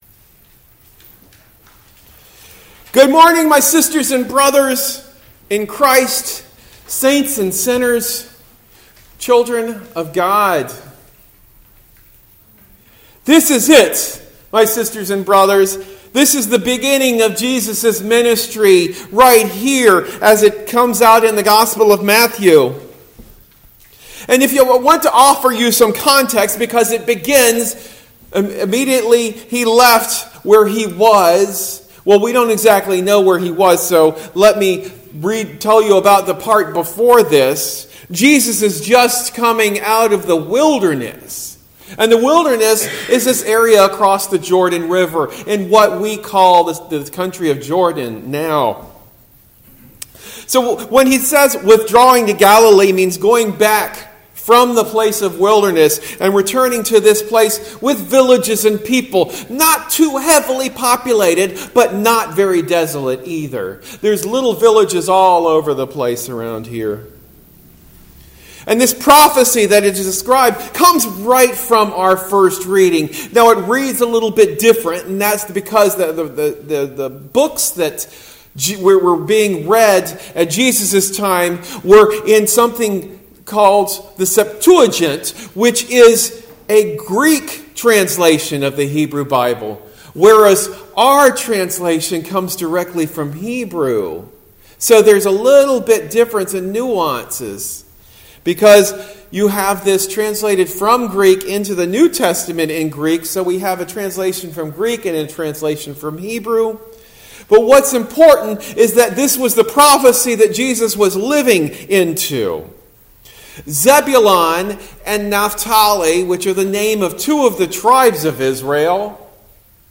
Sermon delivered at Lutheran Church of the Cross in Berkeley.